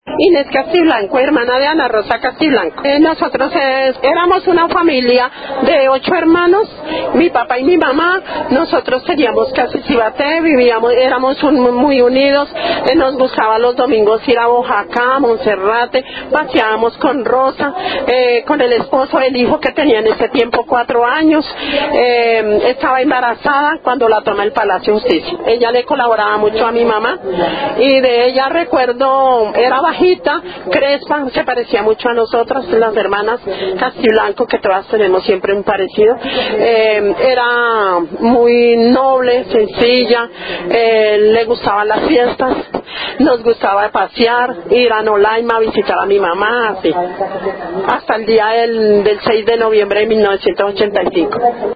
A través de las palabras y voces de los  familiares de las víctimas, recordamos a cada uno de los desaparecidos del Palacio de Justicia: